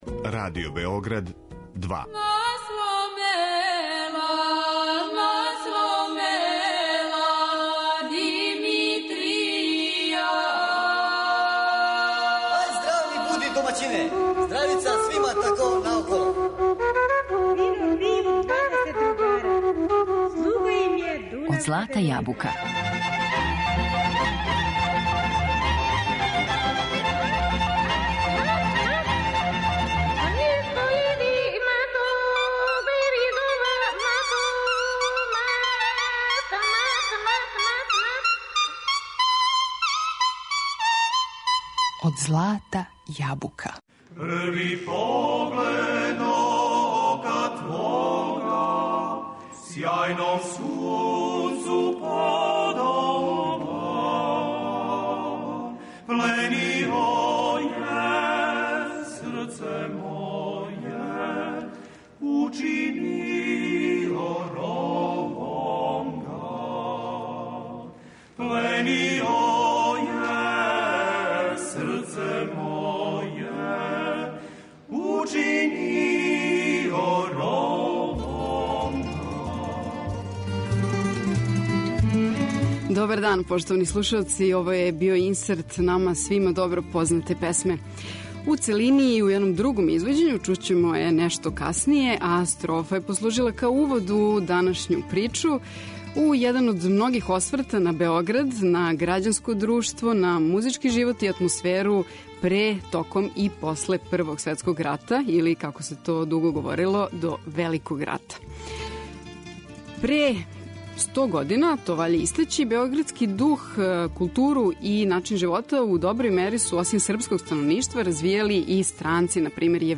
У емисији говоримо о овој породици и слушамо песме и мелодије из збирке нота, коју је поводом сто година од почетка Првог светског рата, 2014. објавила издавачка кућа Клио, а збирка заправо представља избор штампаних издања Јована Фрајта.